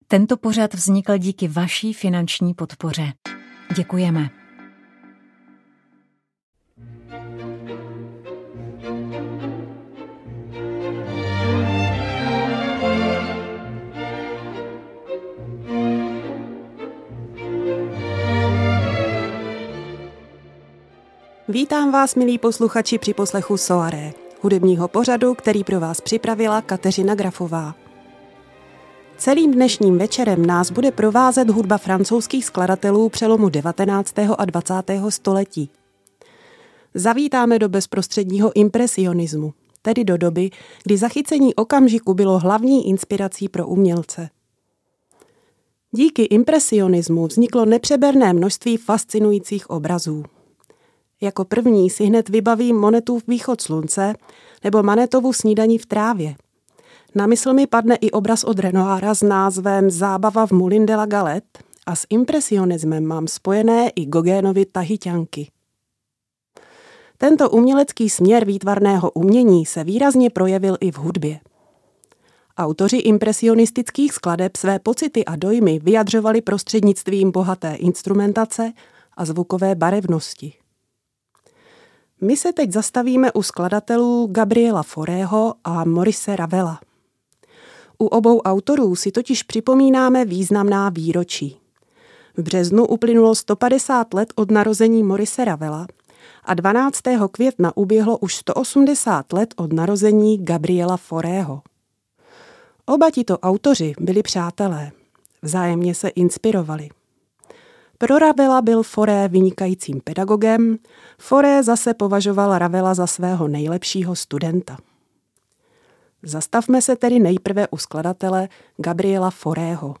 Štědrý večer na Radiu Proglas rozzáří pastorely, radostné písně českých kantorů o narození Ježíše Krista, které svou prostou ale zároveň neobyčejně líbivou melodii přispějí k pokojnému a láskyplnému prožití Vánoc